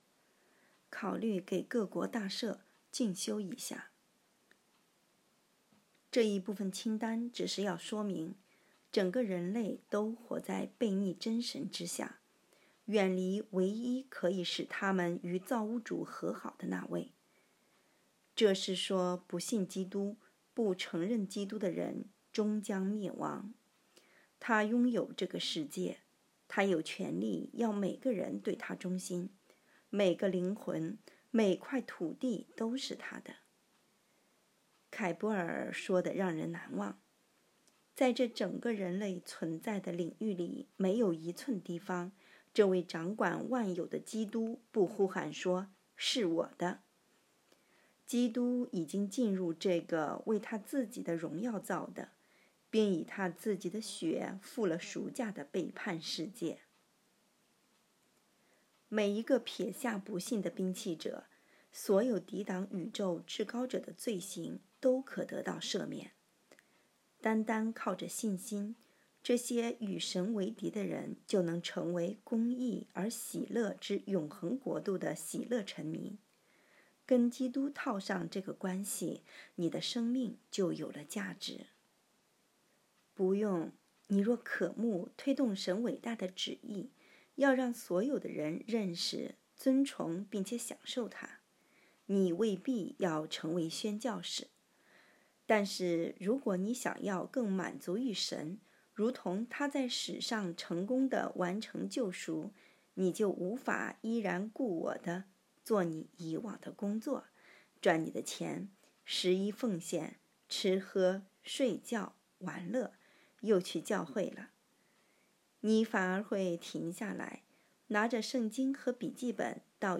2024年10月17日 “伴你读书”，正在为您朗读：《活出热情》 欢迎点击下方音频聆听朗读内容 音频 https